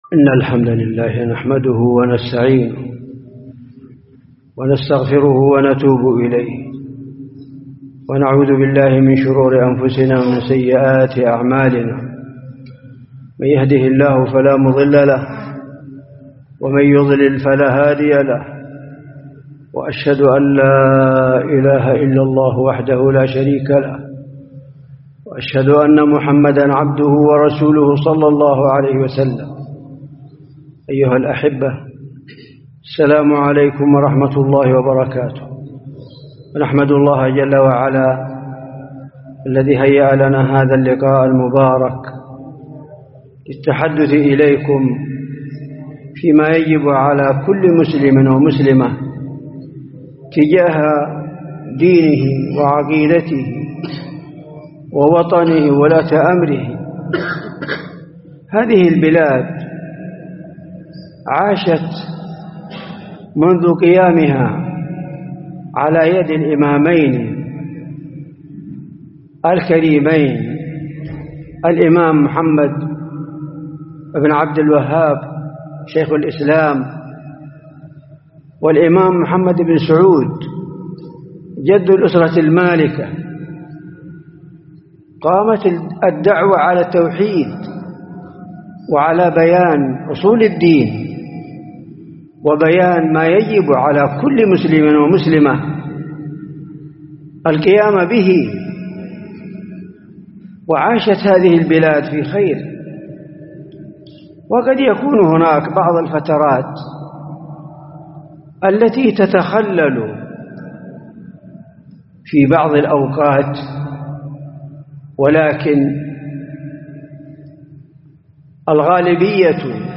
محاضرة
جامع الودعاني بمحافظة العارضة